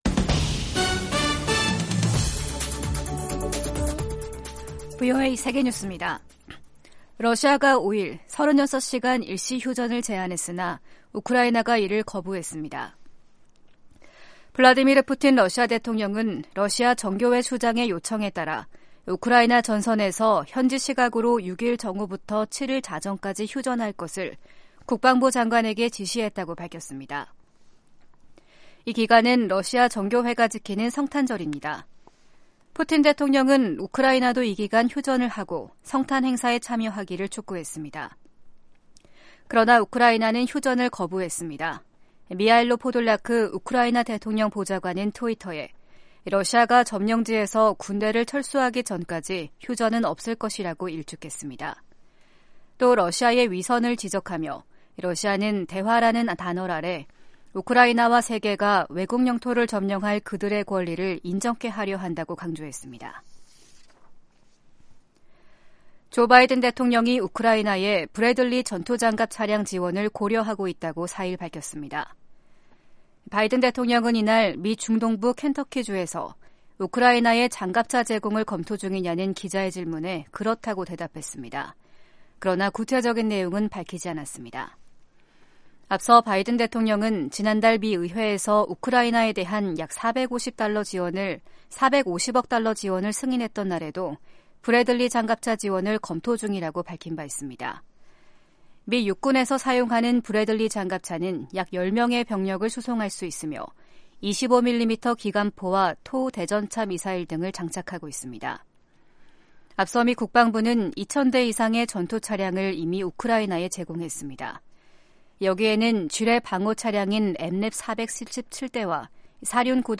세계 뉴스와 함께 미국의 모든 것을 소개하는 '생방송 여기는 워싱턴입니다', 2023년 1월 6일 아침 방송입니다. 프랑스가 우크라이나에 전투차량을 지원하겠다고 약속했습니다.